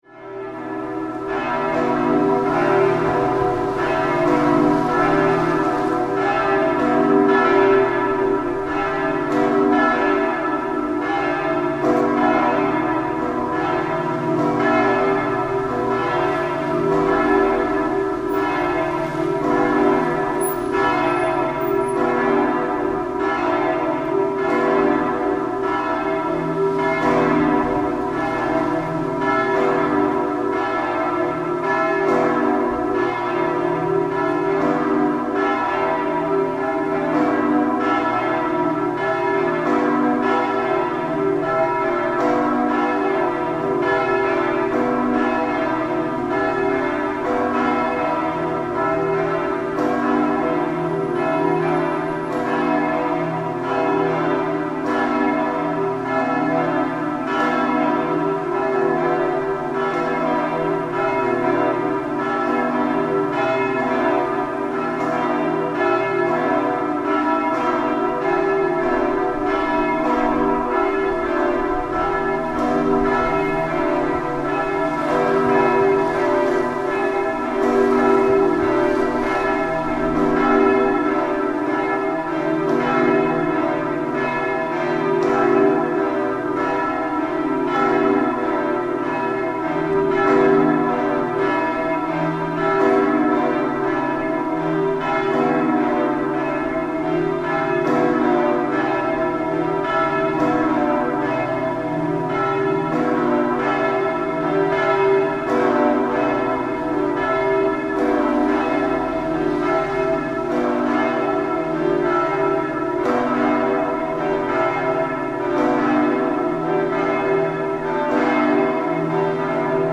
Huge pre-communion service bells ring out from the Berliner Dom cathedral across the Mitte district of Berlin.
A solid eight minutes of impressively full bell sounds that reverberate through your entire body. As the bells fade, we walk towards the fountain in front of the cathedral.